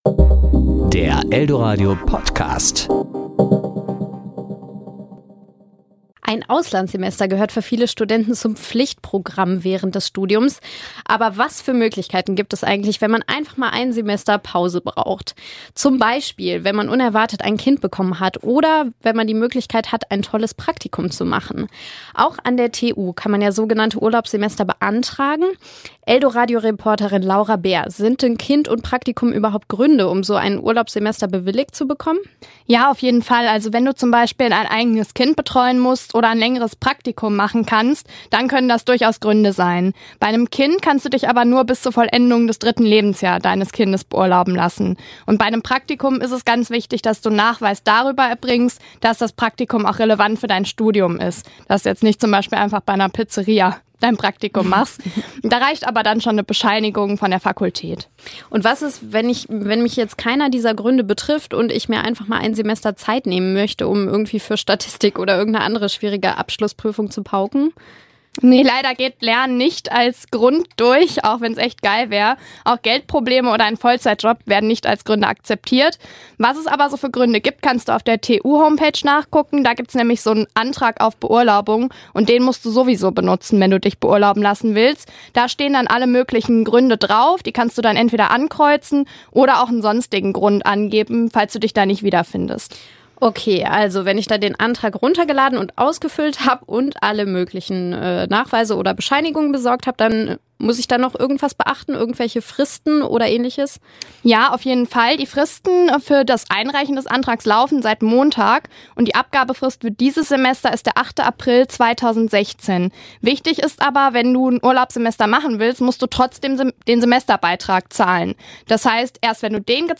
Kollegengespräch  Ressort